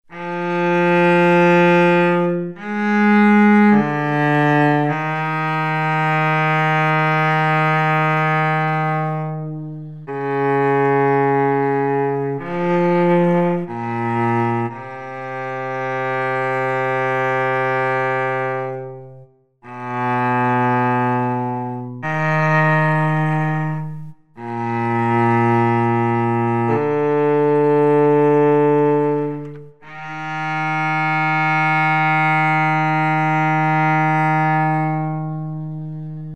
cello notes
essaouira-cello.mp3
You can hear all the noise form the arc and the instrument itself!